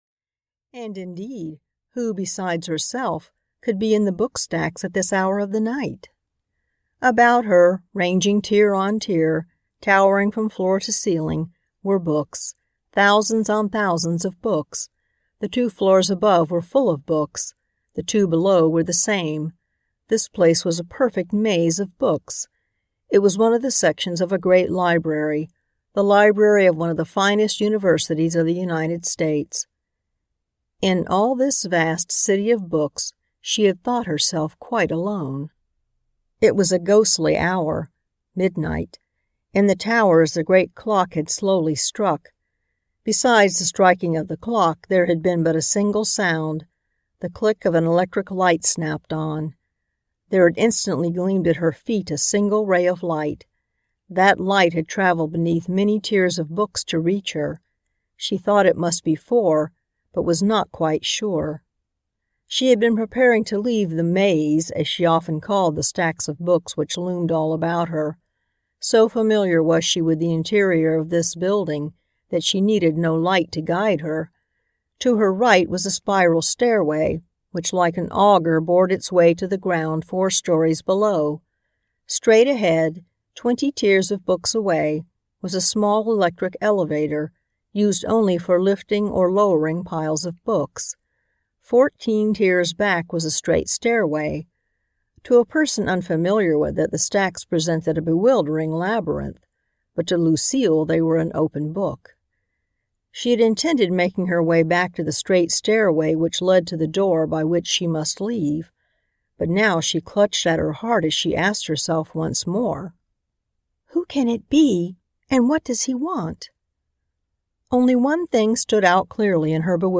The Secret Mark (EN) audiokniha
Ukázka z knihy
the-secret-mark-en-audiokniha